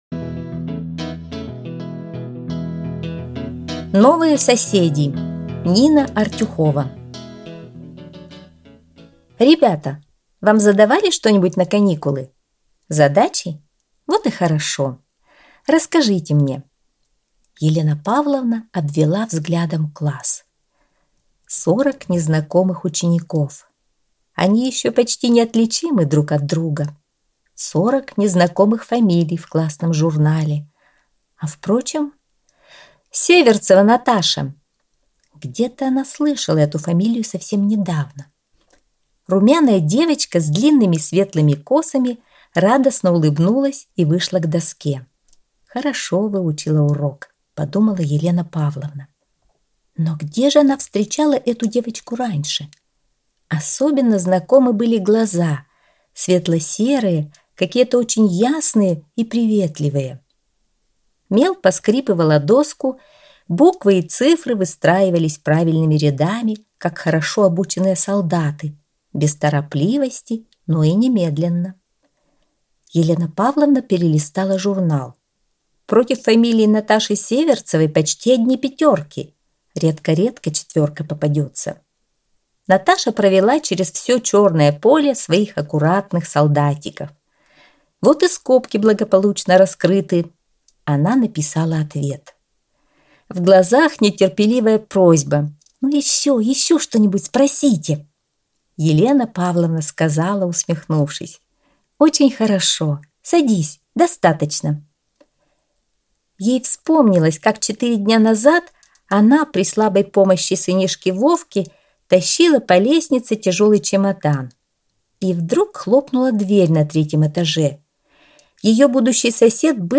Новые соседи - аудио рассказ Артюховой - слушать онлайн